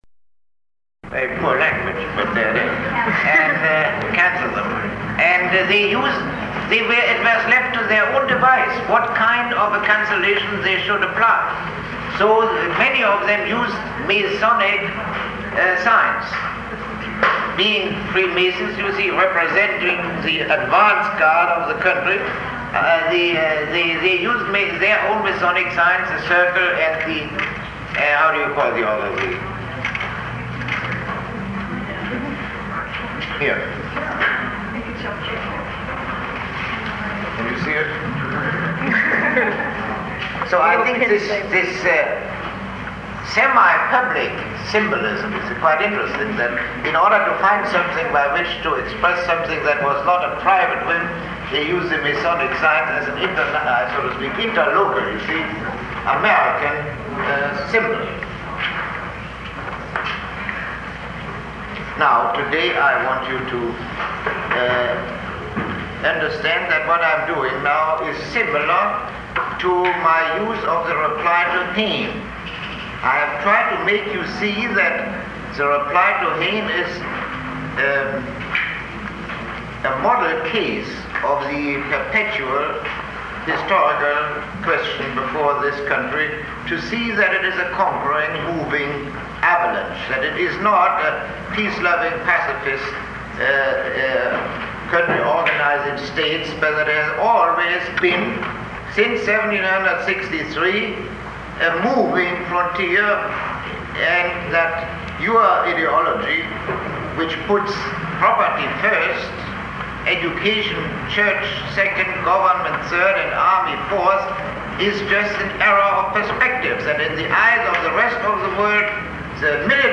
Lecture 36